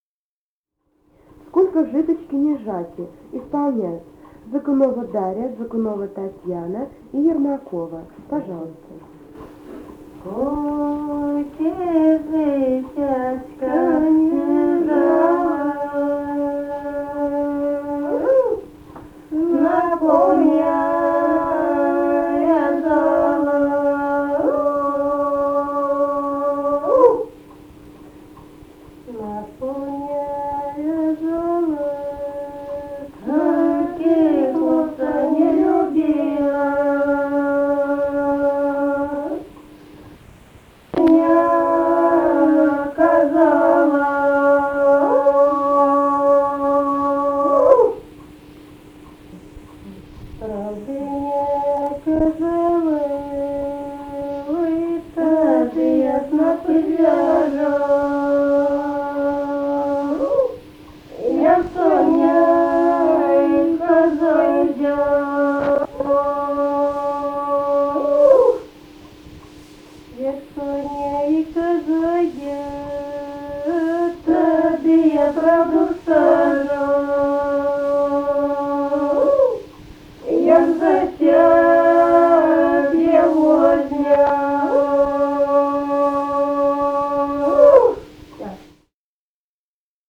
Музыкальный фольклор Климовского района 053. «Сколько житечка ни жала» (жнивная).
Записали участники экспедиции